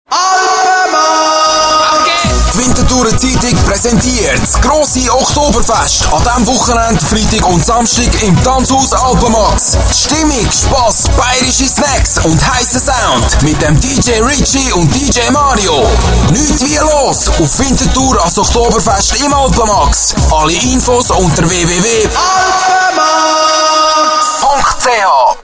Stimme variabel (von jugendlich frisch über sachlich-seriös bis reisserisch-auffordernd).
gugendlich, frisch, Kroatisch, Bosnisch, Schweizerdeutsch, Sprecher, dynamisch, seriös, reisserisch, professionell, eigenes Aufnahmestudio
Sprechprobe: Werbung (Muttersprache):